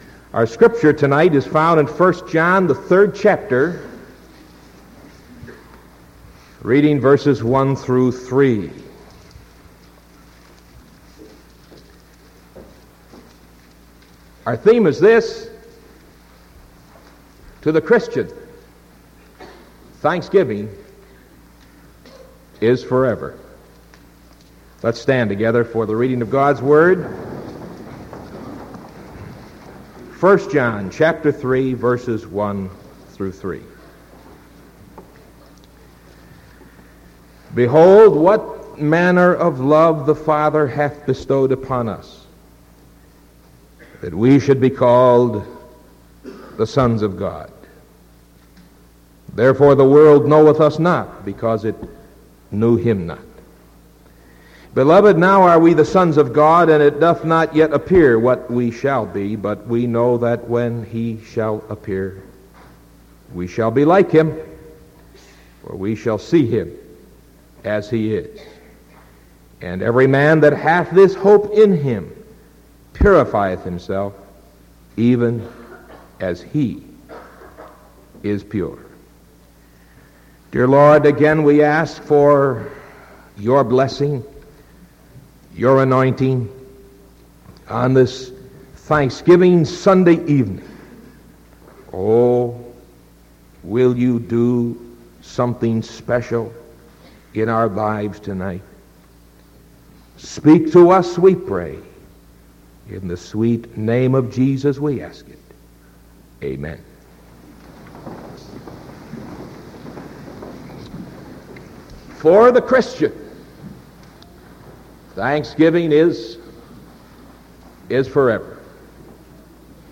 Sermon November 23rd 1975 PM